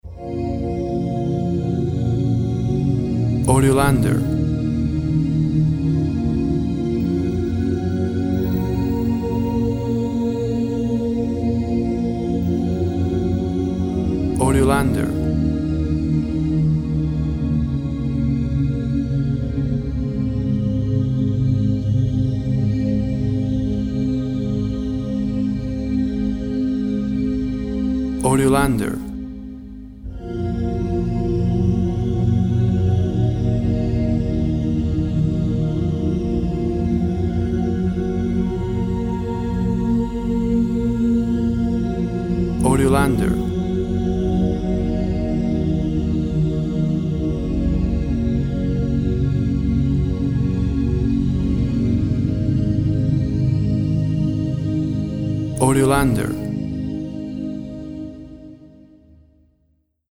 Thick organ sounds.
Tempo (BPM) 42